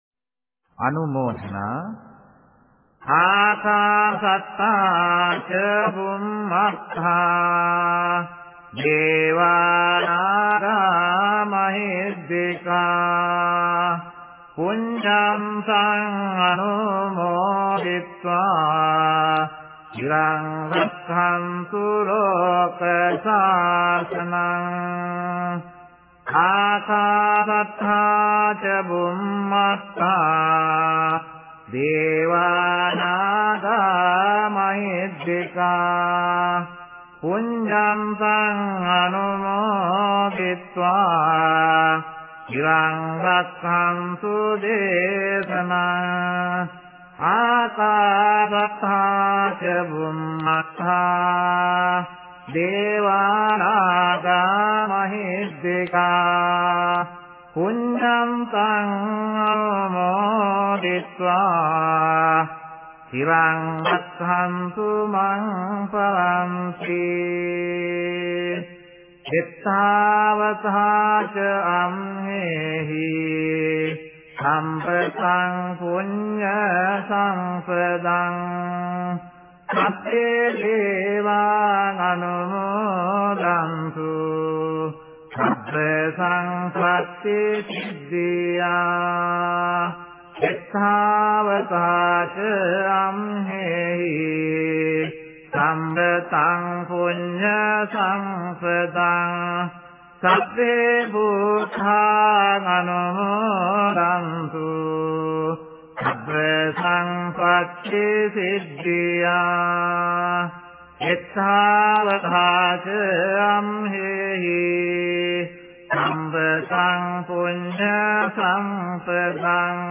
饶益一切天人--巴利文佛教歌曲